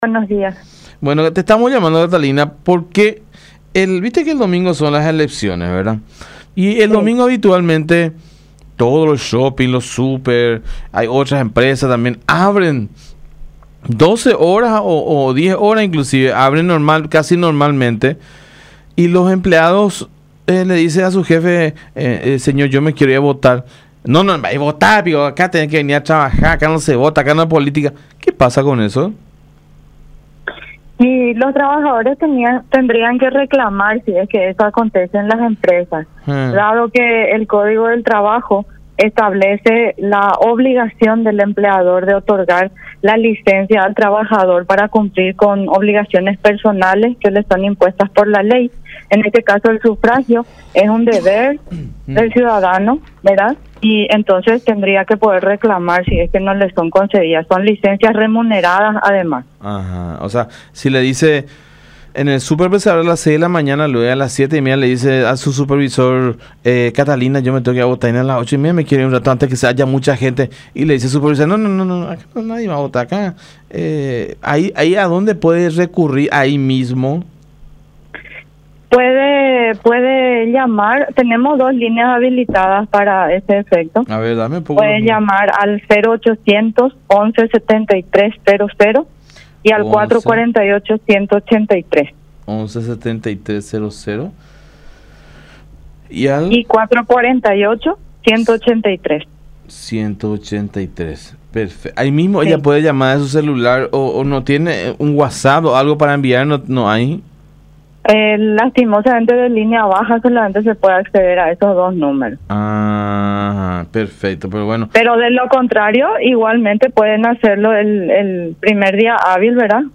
“El Código del Trabajo establece la obligación del empleador de otorgar la licencia al trabajador para cumplir con obligaciones personales que le son impuestas por la Ley”, explicó en comunicación con La Unión R800 AM, resaltando que, en el caso del sufragio, es un deber del ciudadano.